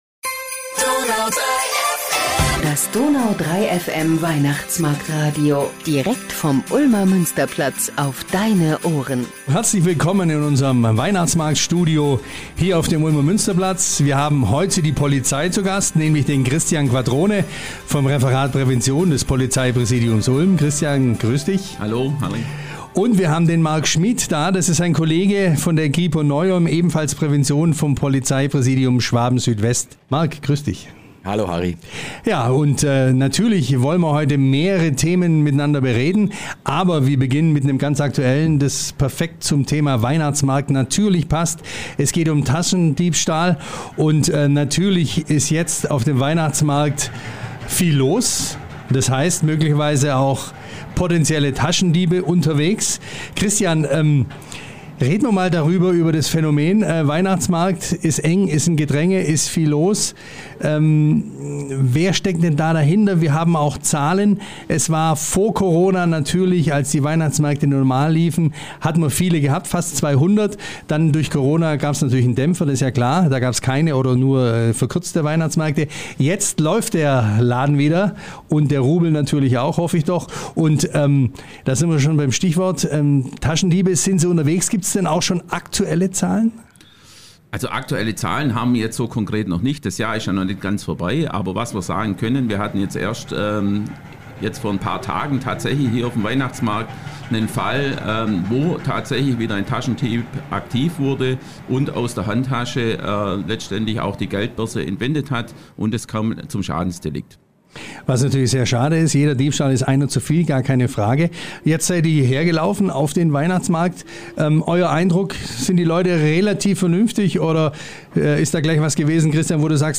Was tun gegen Taschendiebe und Einbrecher? Die Polizei zu Gast im Weihnachtsmarktradio!